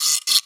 Guiro2x.wav